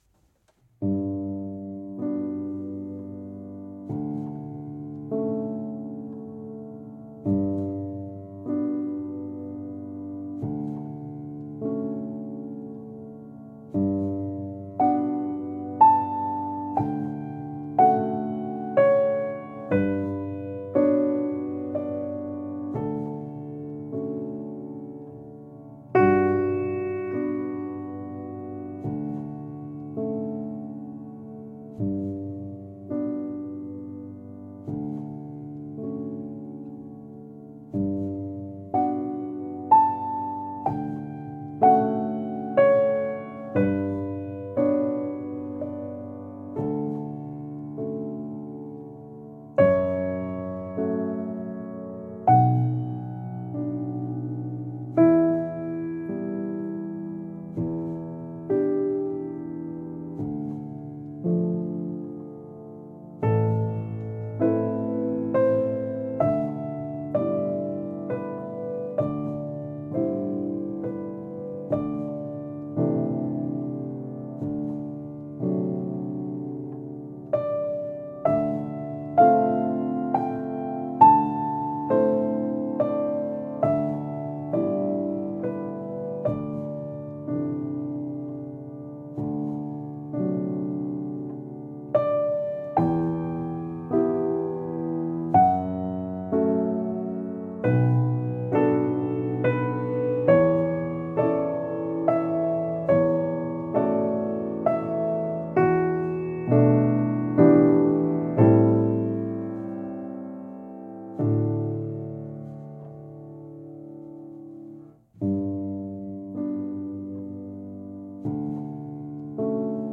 Klavier: Bösendorfer 180 Bj. 1919